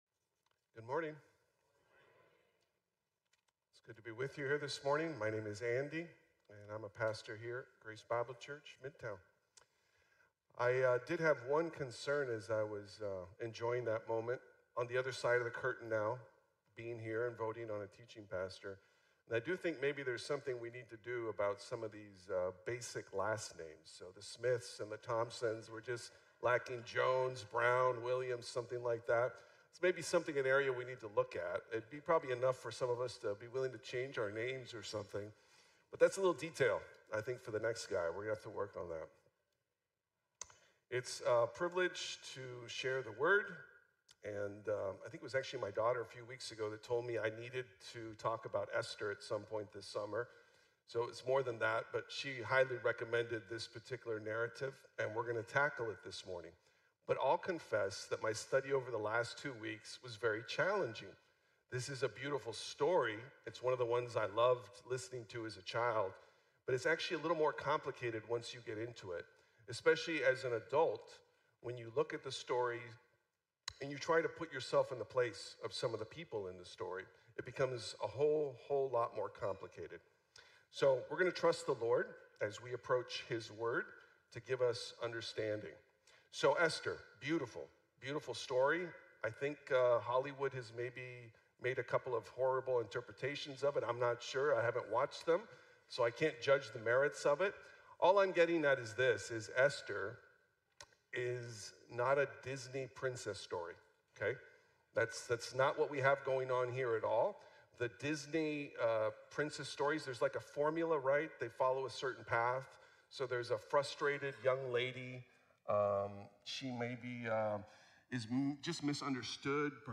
Esther: For Such a Time | Sermon | Grace Bible Church